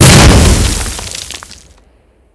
GrenExpl07.wav